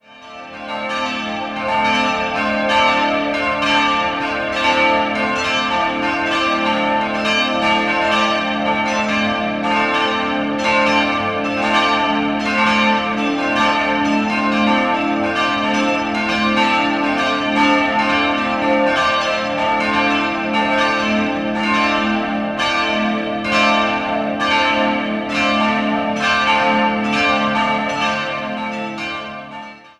Die evangelisch-lutherische Osterkirche mit ihrem zum Teil holzverkleideten Äußeren wurde im Jahr 1978 errichtet. 4-stimmiges ausgefülltes A-Moll-Geläut: a'-c''-d''-e'' Die Glocken wurden 1978 von der Gießerei Perner in Passau gegossen.